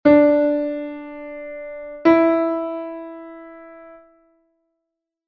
RE-MI